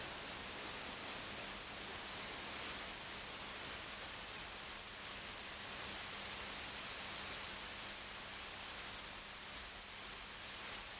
There is some noise at the low frequencies, with the 200 Hz standing out.
I have recorded the signals shown above, but please keep in mind that I’ve enabled Automatic Gain Control (AGC) to do so to make it easier for you to reproduce them.
10% Fan Speed